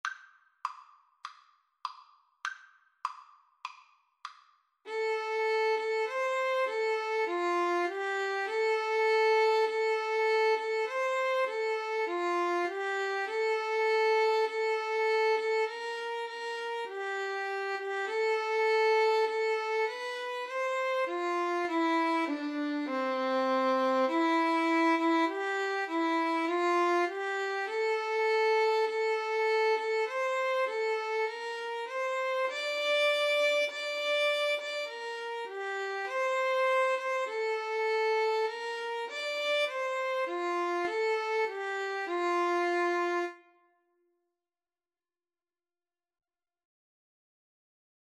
F major (Sounding Pitch) G major (Clarinet in Bb) (View more F major Music for Clarinet-Violin Duet )
4/4 (View more 4/4 Music)
Classical (View more Classical Clarinet-Violin Duet Music)